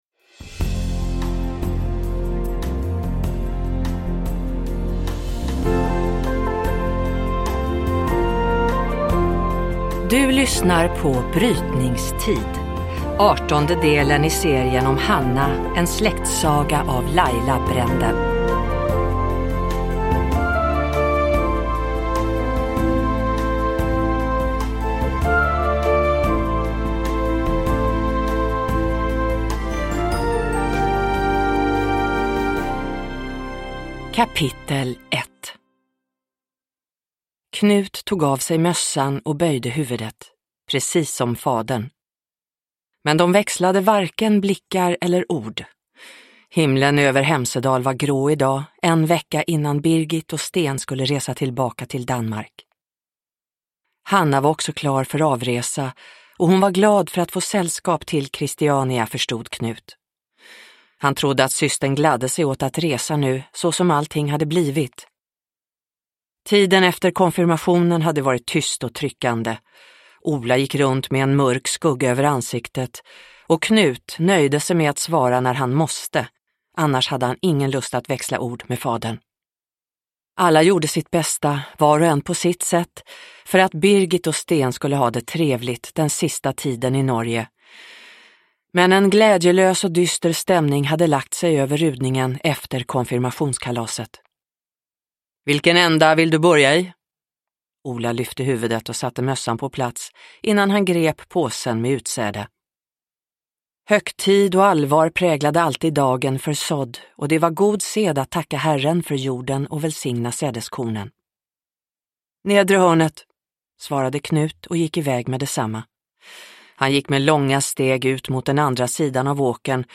Brytningstid – Ljudbok – Laddas ner